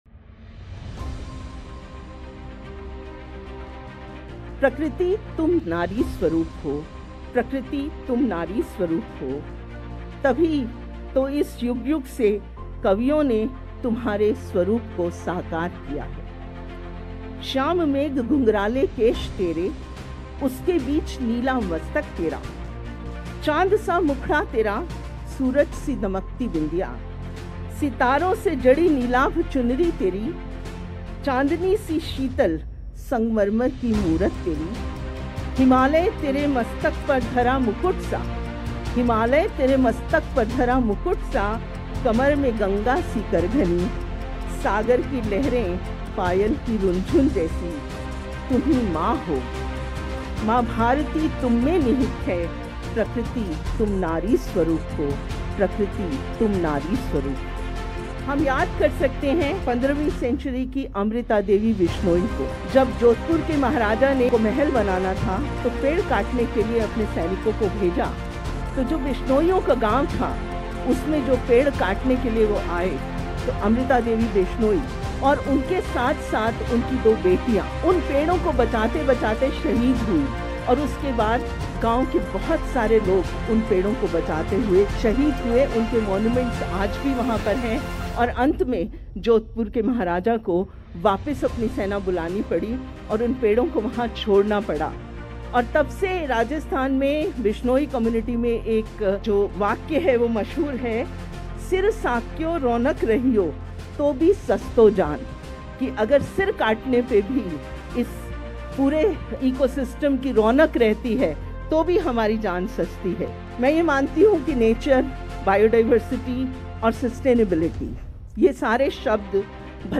The Highlights Of Speech Episodes